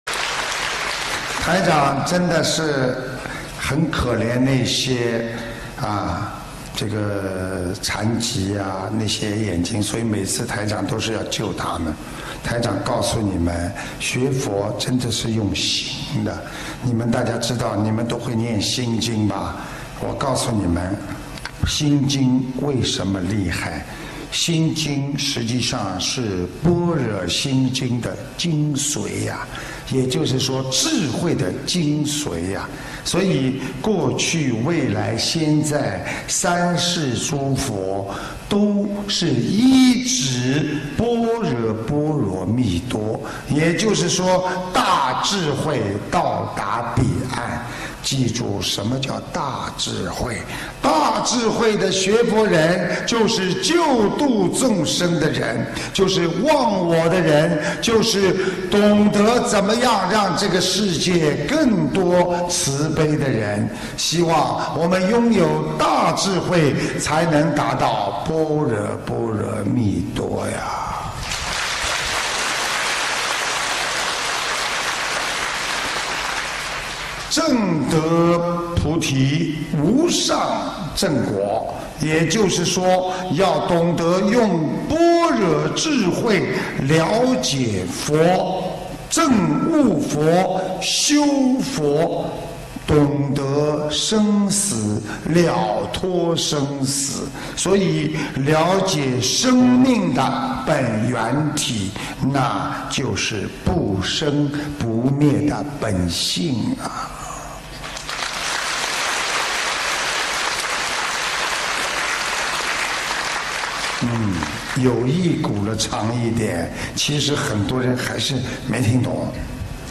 马来西亚槟城